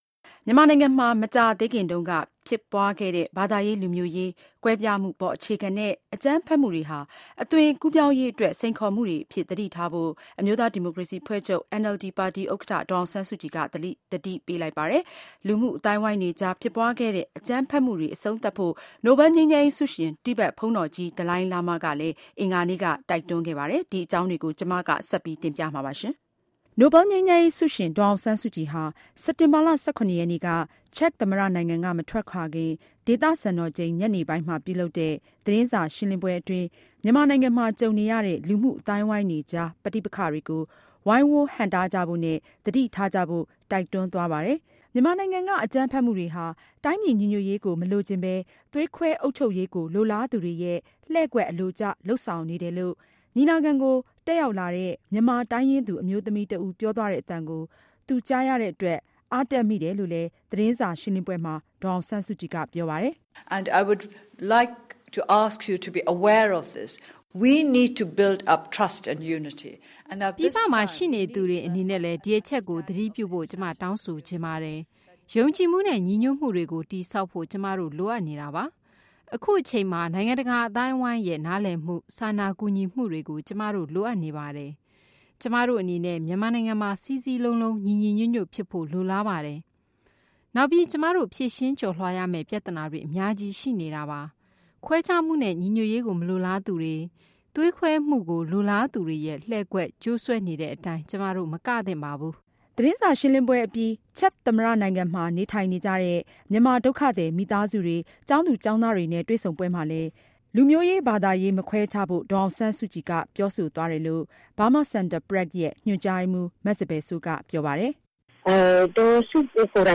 ဒေါ်စုရဲ့ သတင်းစာရှင်းလင်းပွဲ